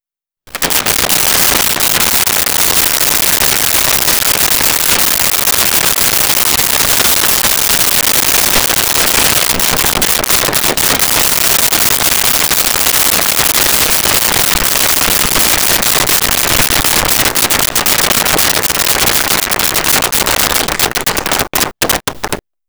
Crowd Applause
Crowd Applause.wav